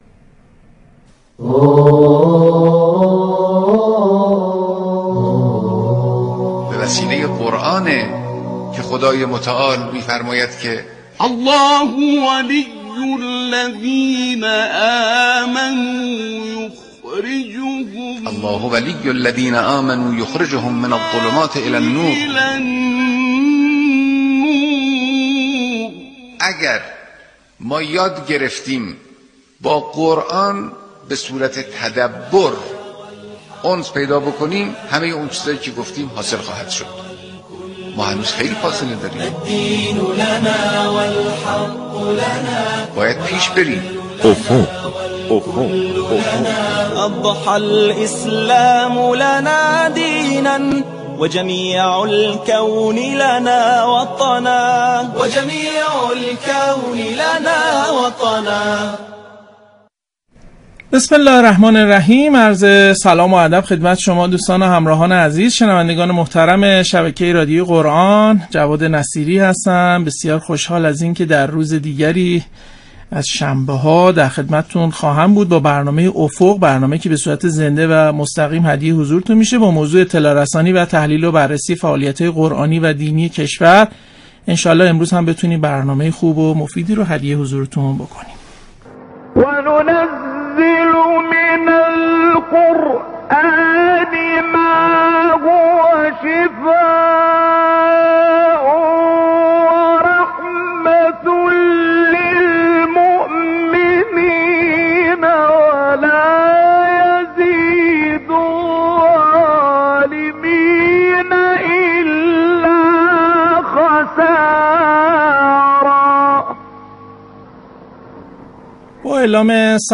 به صورت تلفنی به بیان نکاتی در مورد فعالیت‌های قرآنی در حوزه بانوان پرداخت.